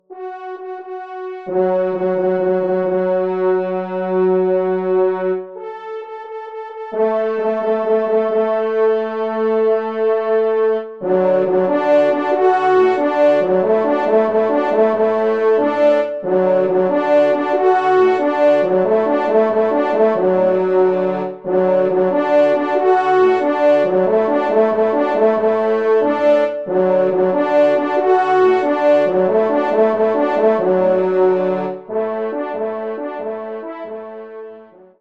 Genre : Divertissement pour Trompes ou Cors
Pupitre 2° Cor